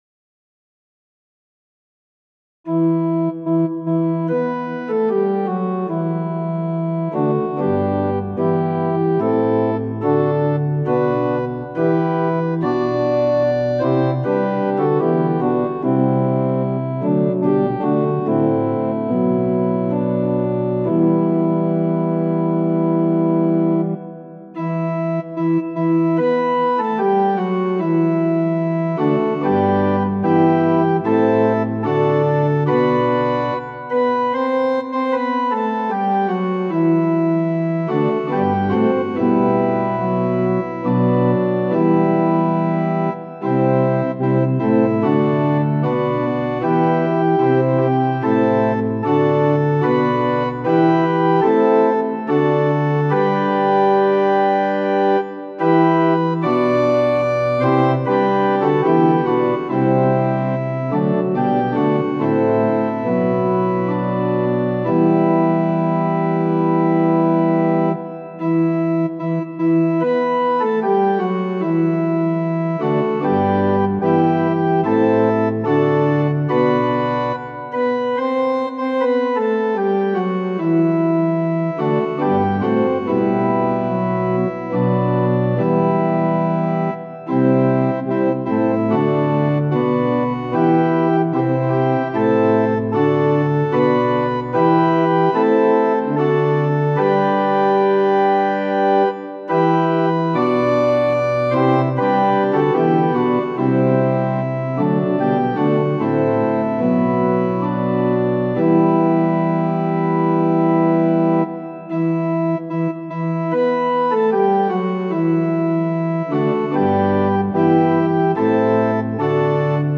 ♪賛美用オルガン伴奏音源：
・柔らかい音色部分は前奏です
・はっきりした音色になったら歌い始めます
・節により音色が変わる場合があります
・間奏は含まれていません
Tonality = e
Pitch = 440
Temperament = Equal